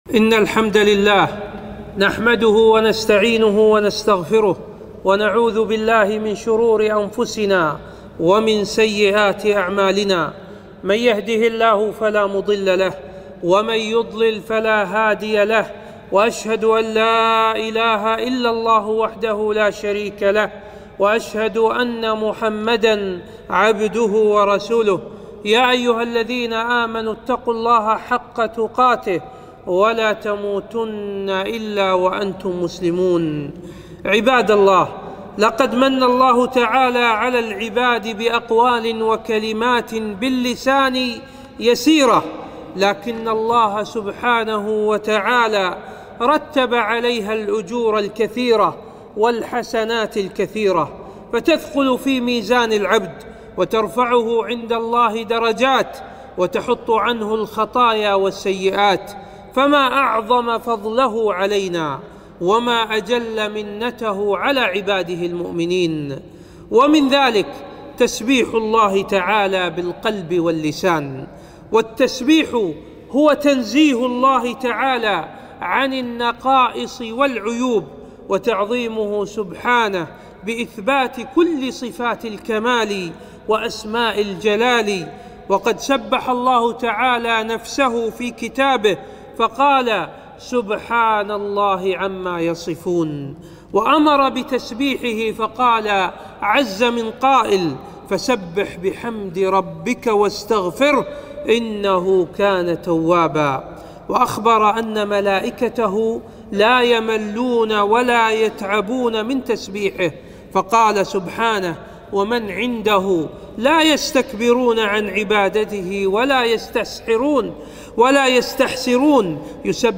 خطبة - منزلة التسبيح وفضله